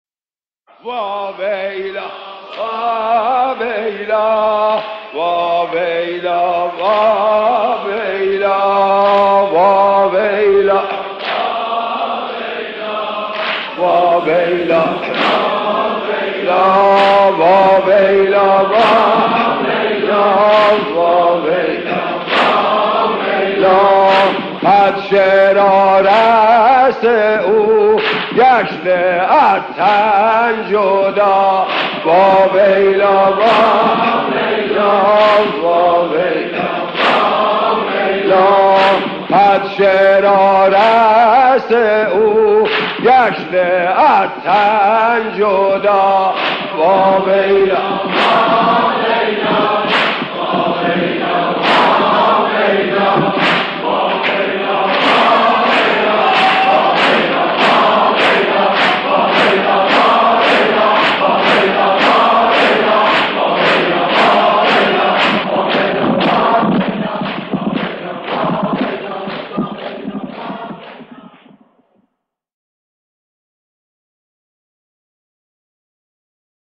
صوت | نغمه‌های عاشورایی از مداحان قدیم تهران
بخشی از دم سینه‌زنی از زبان حضرت رقیه(س)